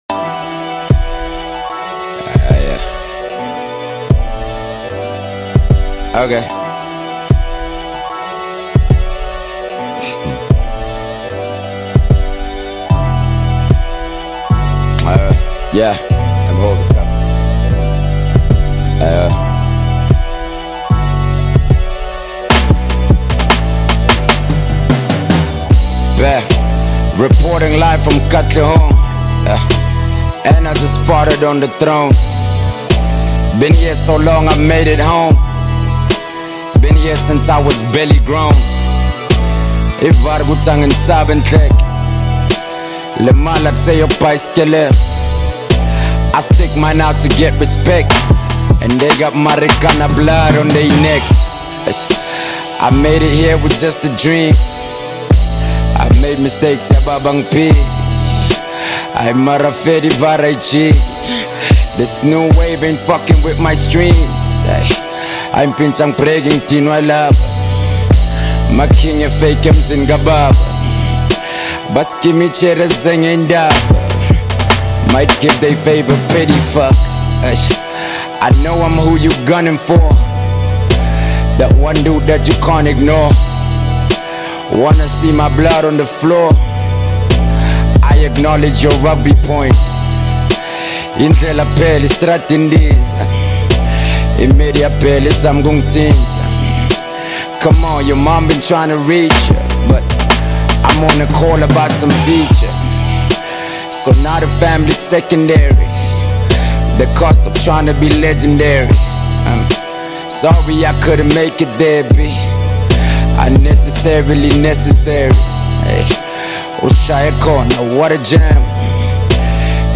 SA award-winning platinum rapper
hip-hop track